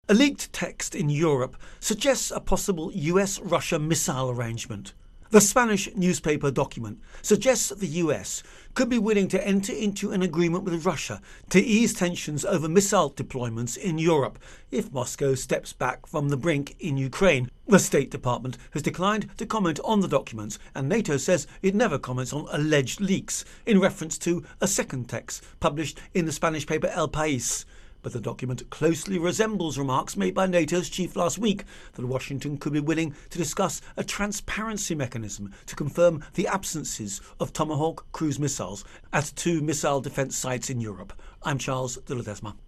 Ukraine-Tensions-Leaked Documents Intro and Voicer